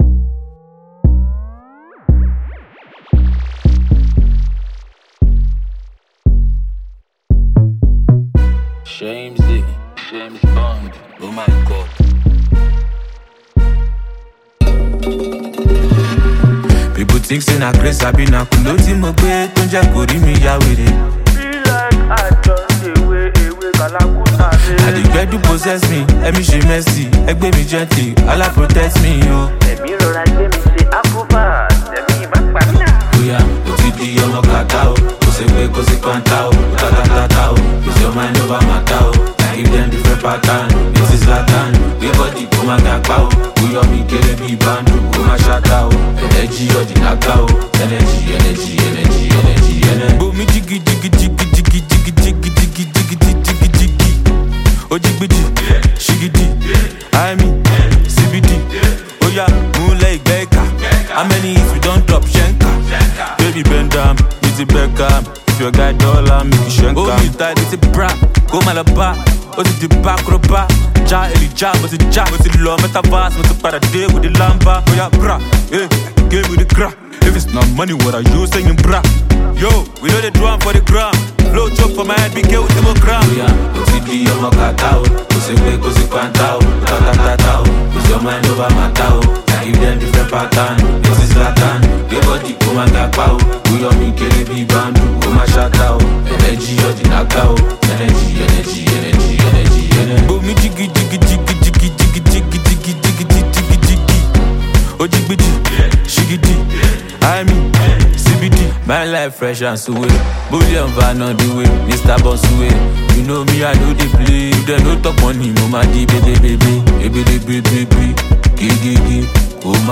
a Nigerian rapper and singer